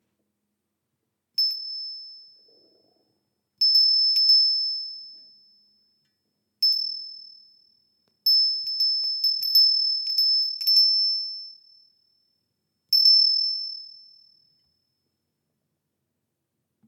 Japanese lantern Wind Chime made from cast iron
Japanese Lantern Wind Chime – NEW!
The deep black cast iron gives it a sturdy and graceful feel, complimented by the tranquil sound of the bell.
1.5” tall, 1.75” diameter
Lantern-Windchime.mp3